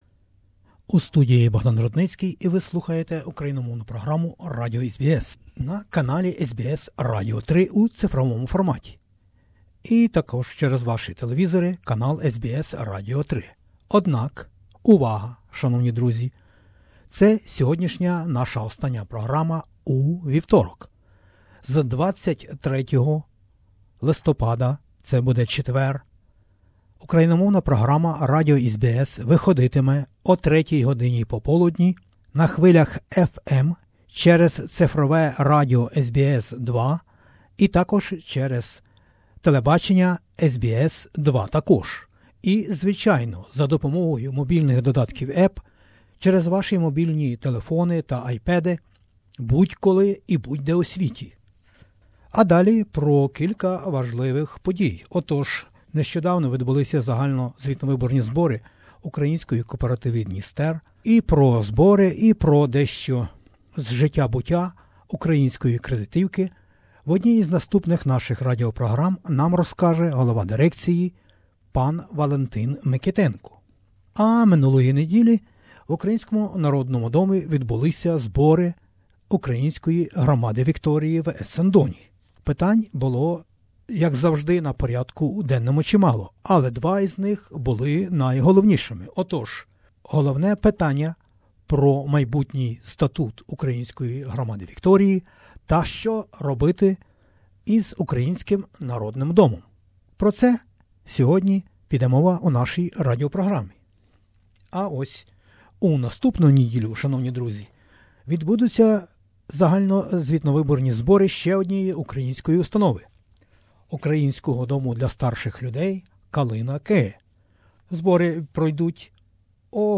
THE ANNUAL GENERAL MEETING OF THE ASSOCIATION OF UKRAINIANS IN VICTORIA (AUV), Ukrainian House, Essendon, 12 November 2017